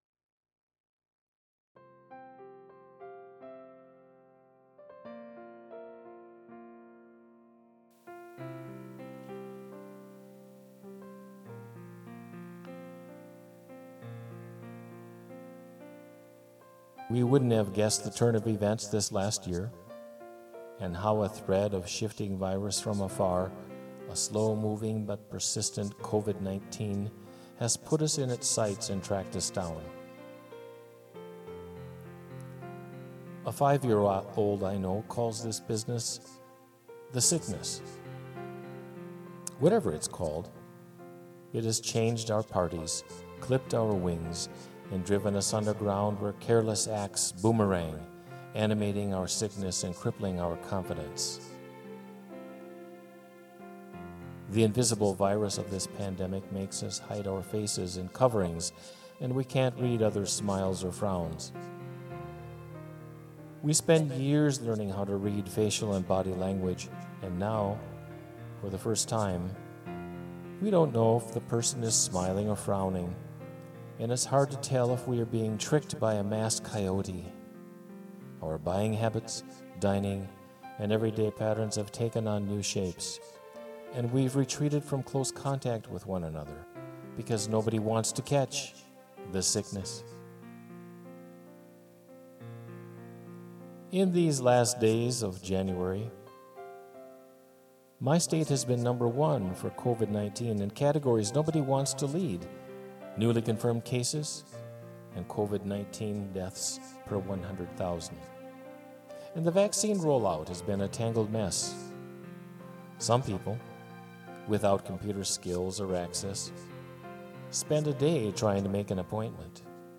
spoken word and music